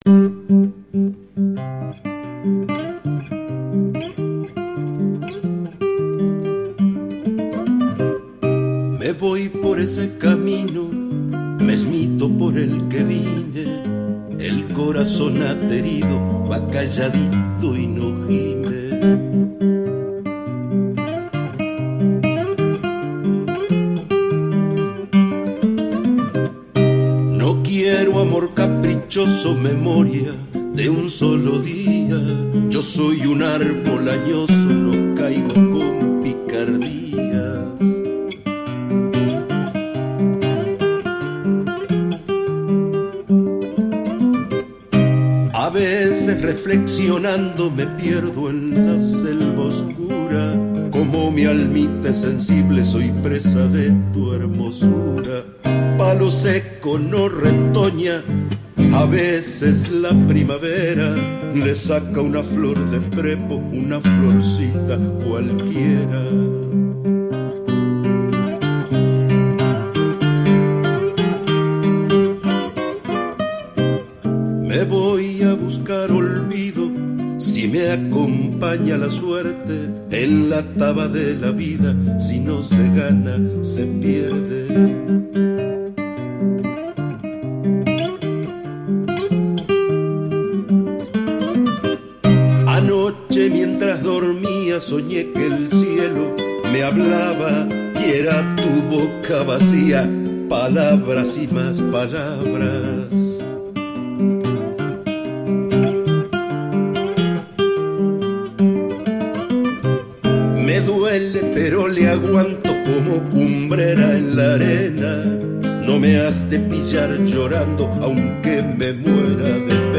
Chacarera